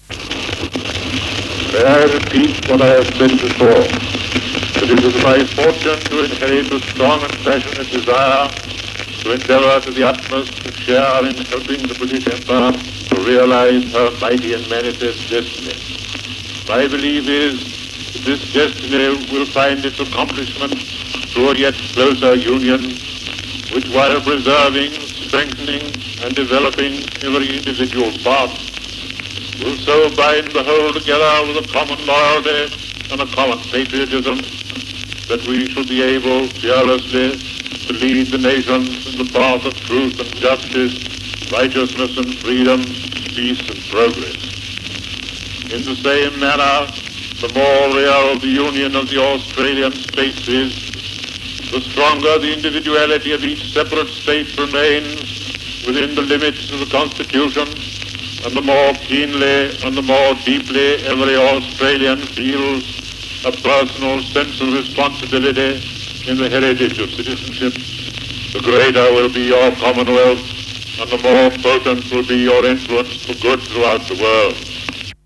Speech by Lord Tennyson, Governor of South Australia